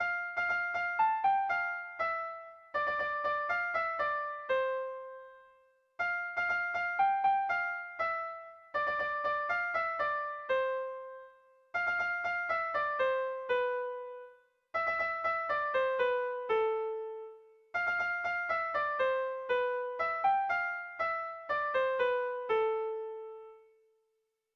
Dantzakoa
Kopla handiaren moldekoa
8A / 8B / 10A / 8B